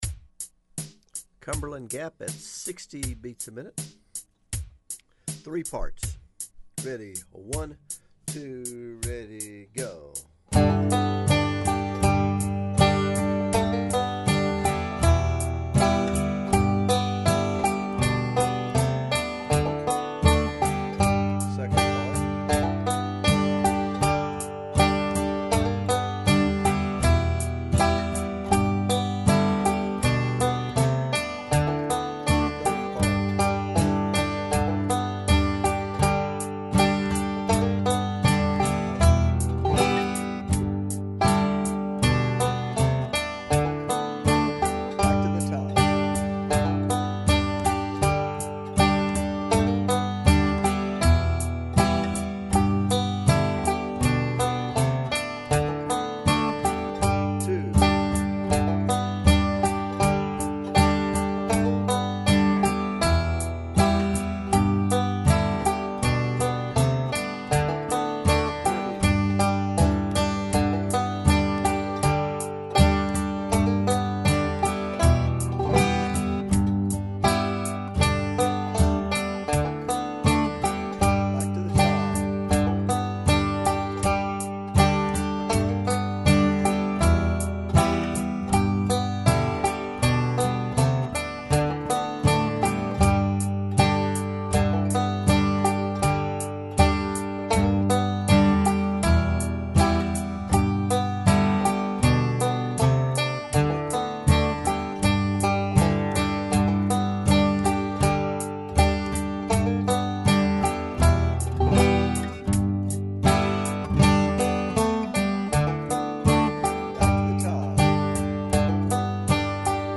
Also, the banjo and guitar tracks are isolated on each side, so either one can be silenced by adjusting the balance all the way to the other side.
cumberland gap at 80 bpm